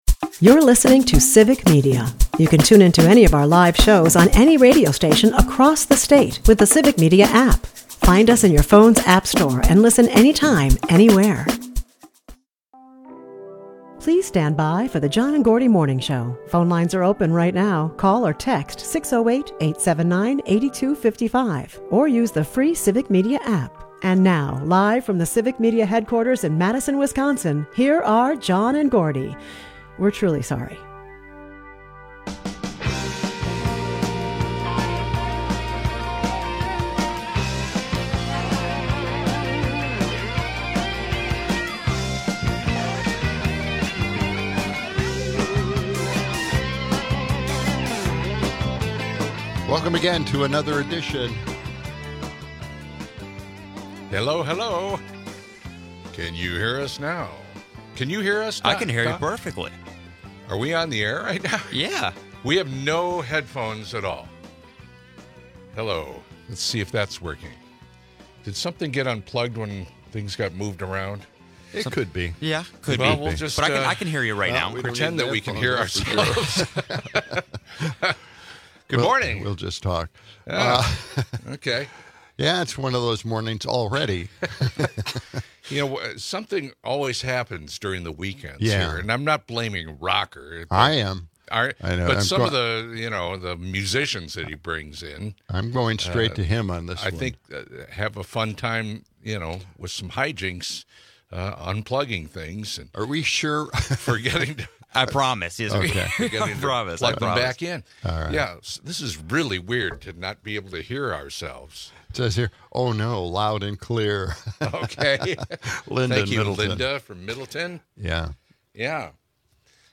Another speaker follows up with a story about her 19-year-old nephew, who had eaten something, and it was frozen solid on his face; he couldn't even tell it was there when she asked him if he had ever heard of a napkin. In this best of, the guys joke about the strange places where an Elvis impersonator might perform at a County Fair, suggesting he'd be right next to the tractor pulls and the carnival clowns, calling it a "choice gig".